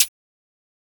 LA Beats Perc.wav